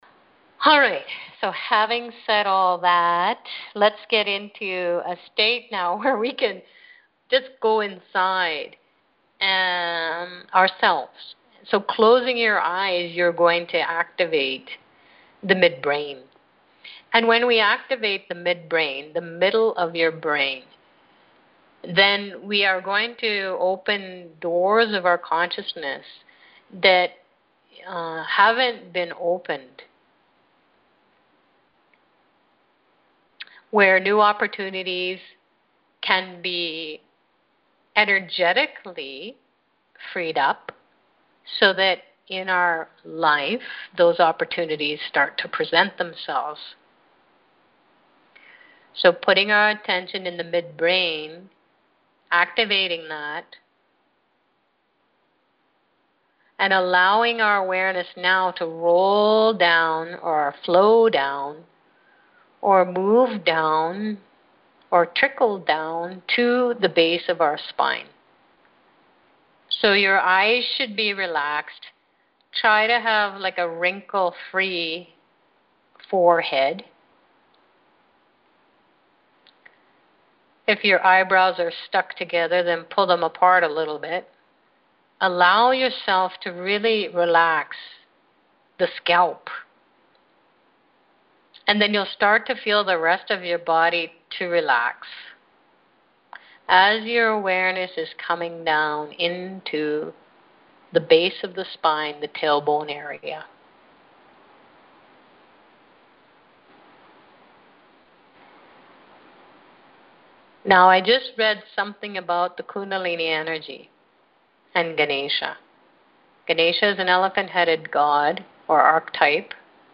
Download the Sunday, September 21st call and meditation for more, including: